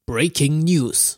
短暂的人声样本" 15秒的"blah blahquot。
这是一个男声在说多个"blah"，因为他在用emphazis描述一些东西
标签： 占位符 模拟语 语音 讲话 胡说 人声
声道立体声